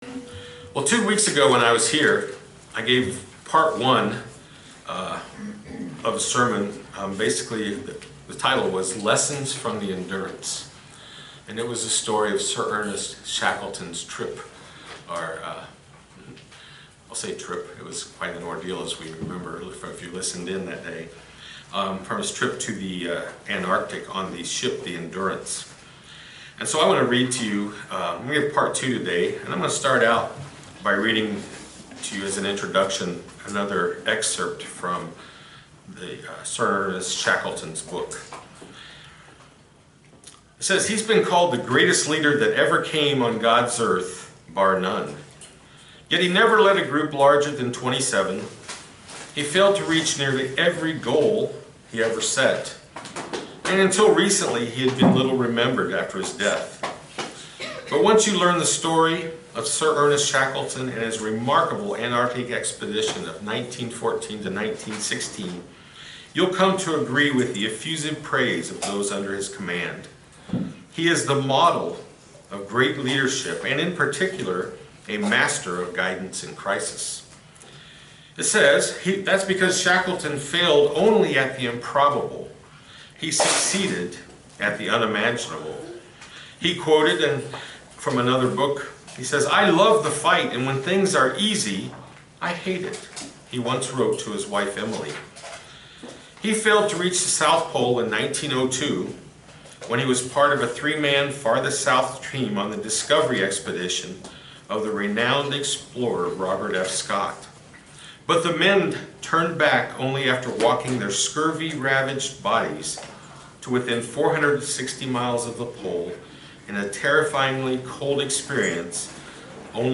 Part two of a two-part sermon. Seven additional lessons we can learn from Sir Ernest Shackleton to apply in our lives to become the type of leader God wants us to be.